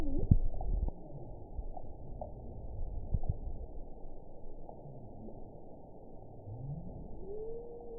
event 921863 date 12/20/24 time 06:43:58 GMT (11 months, 2 weeks ago) score 6.46 location TSS-AB03 detected by nrw target species NRW annotations +NRW Spectrogram: Frequency (kHz) vs. Time (s) audio not available .wav